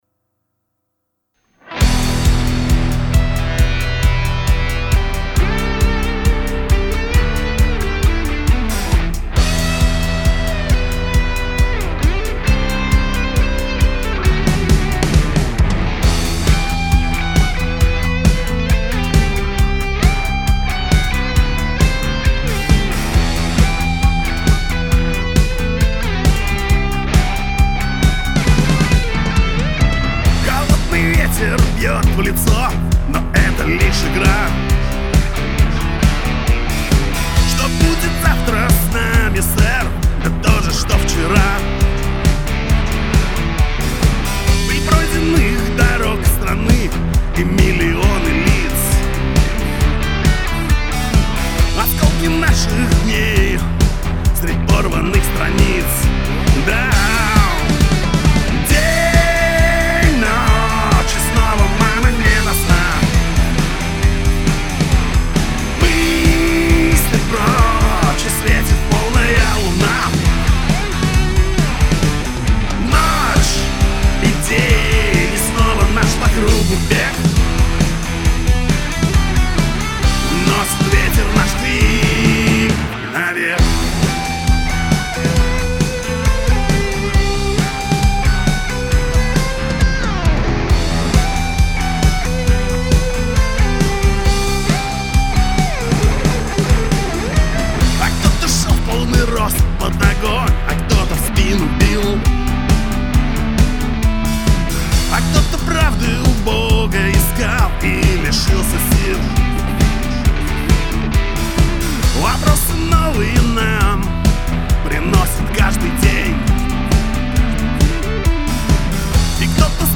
🎸🎸🎸РОК в «КЛЕТКЕ»🎸🎸🎸